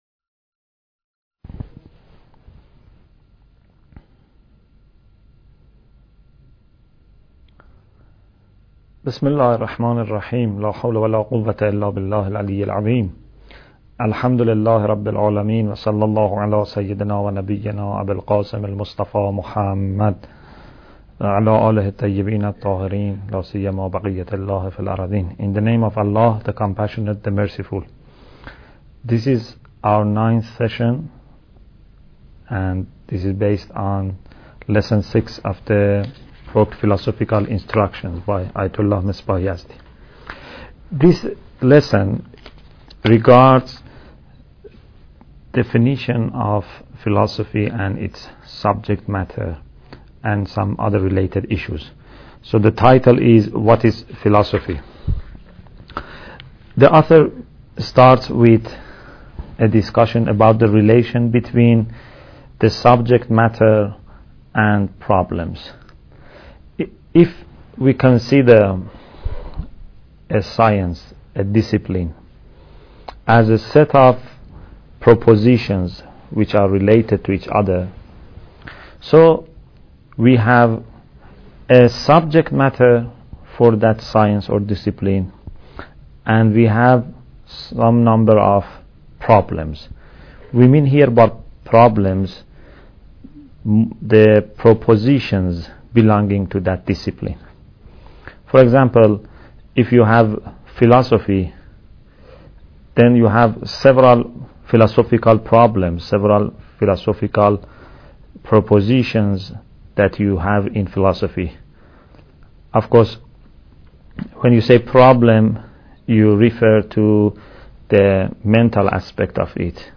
Bidayat Al Hikmah Lecture 9